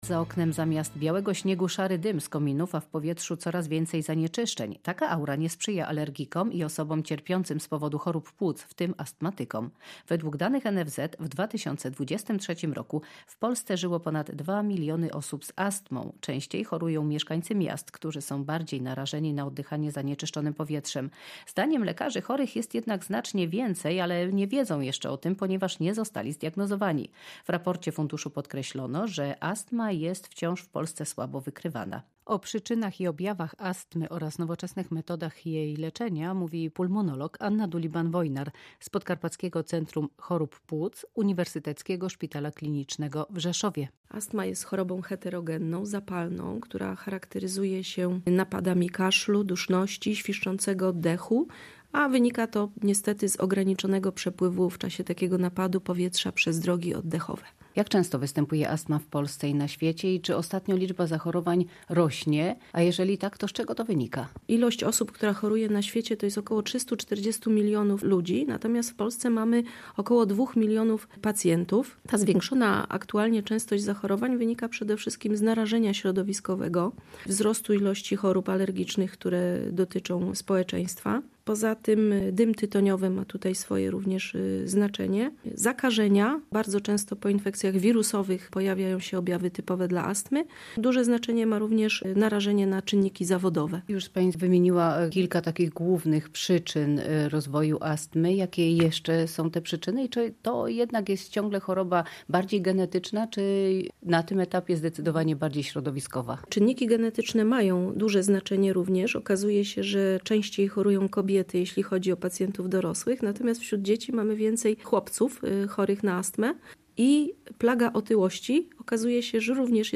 Rozmowa o astmie oskrzelowej